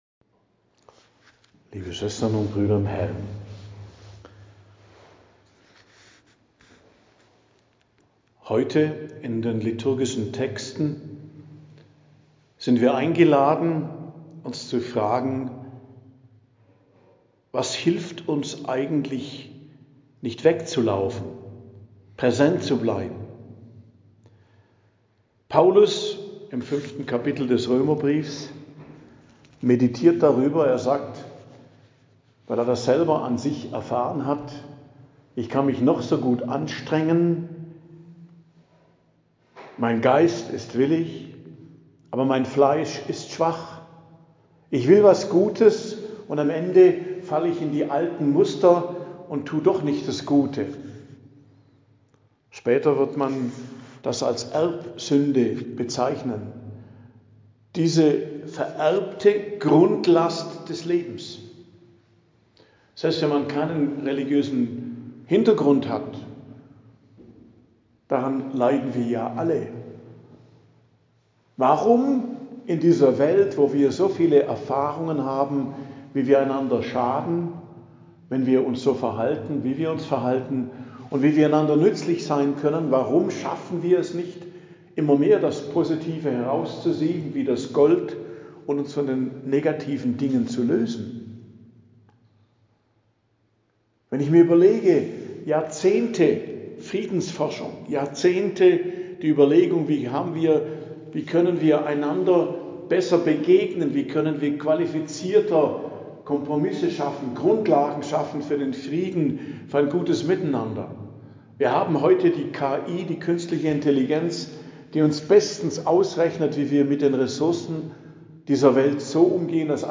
Predigt am Dienstag der 29. Woche i.J., 21.10.2025 ~ Geistliches Zentrum Kloster Heiligkreuztal Podcast